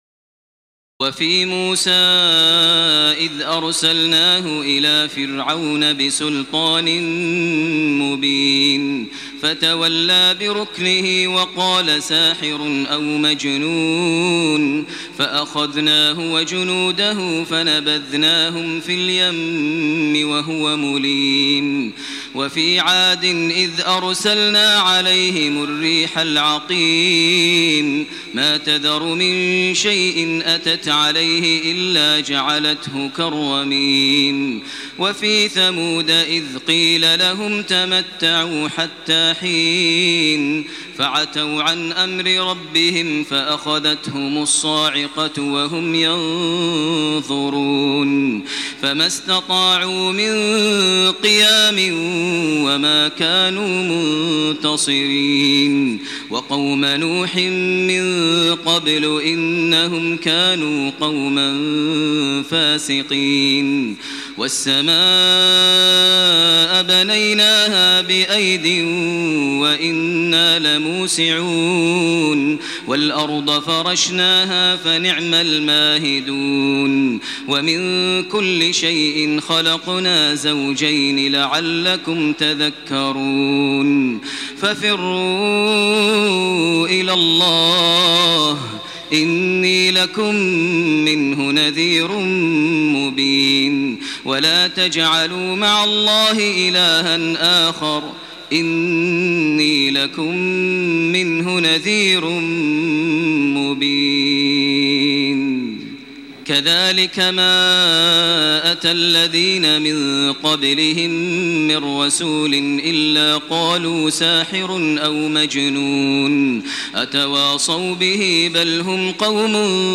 تراويح ليلة 26 رمضان 1428هـ من سور الذاريات (38-60) و الطور و النجم و القمر Taraweeh 26 st night Ramadan 1428H from Surah Adh-Dhaariyat and At-Tur and An-Najm and Al-Qamar > تراويح الحرم المكي عام 1428 🕋 > التراويح - تلاوات الحرمين